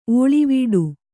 ♪ ōḷivīḍu